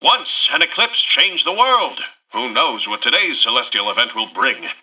Newscaster_headline_03.mp3